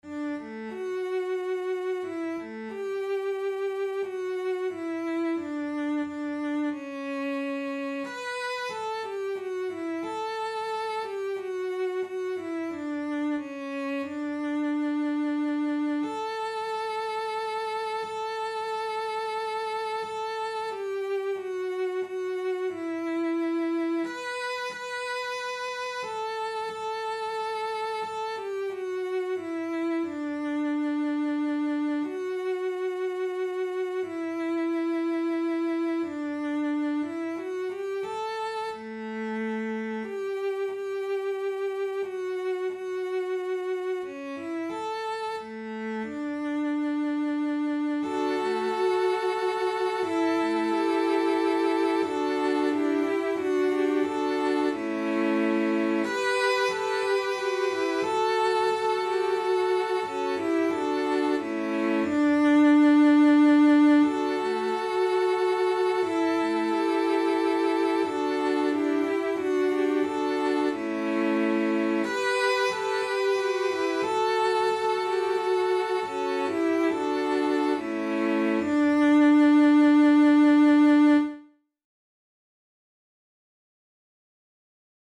Here is a computer generated mp3 recording.